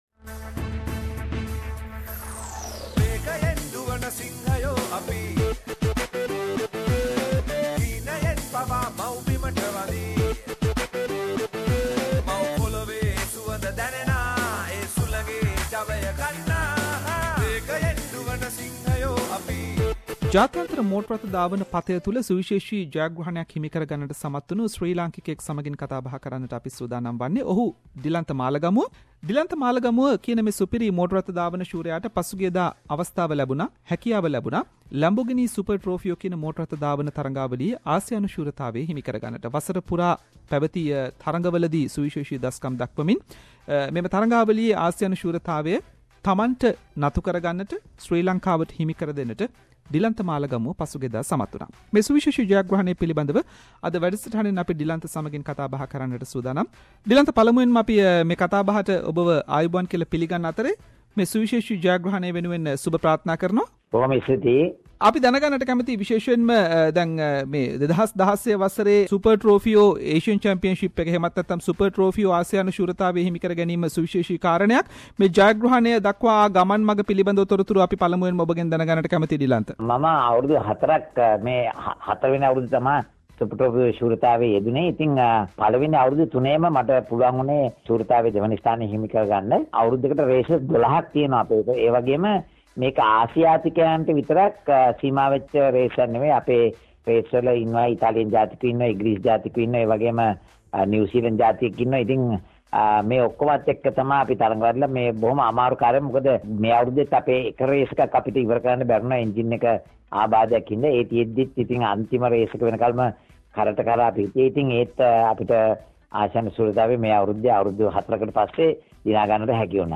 Sri Lankan motor racing champion Dilantha Malagamuwa and his team mate Armaan Ebrahim won the 2016 Lamborghini Super Trofeo Asian championship and SBS Sinhalese interviewed Dilantha regarding this great achievement.